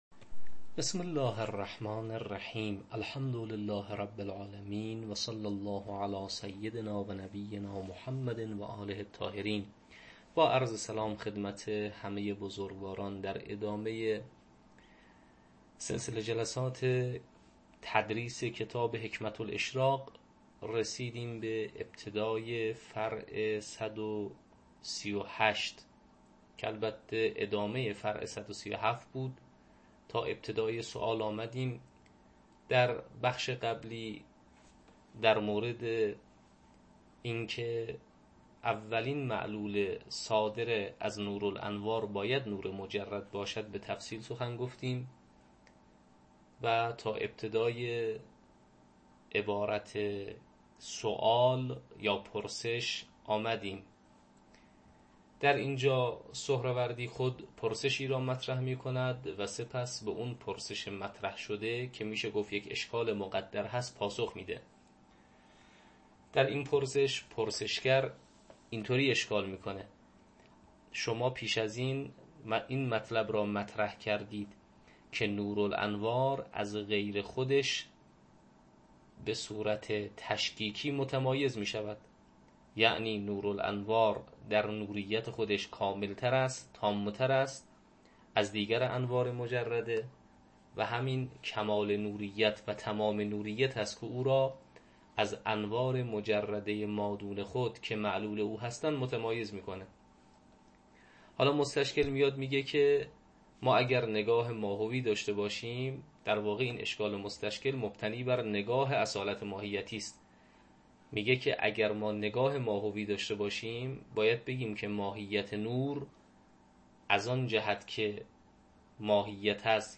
حکمه الاشراق - تدریس